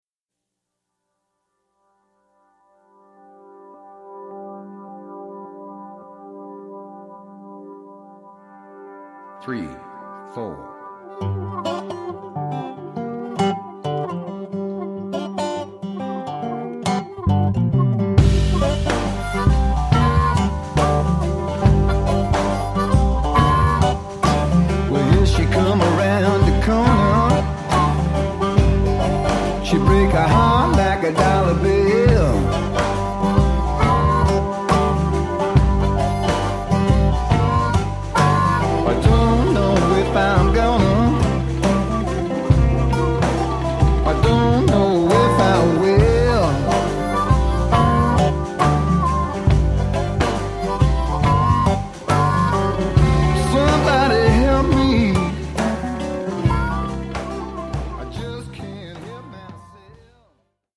The vocal is the first take.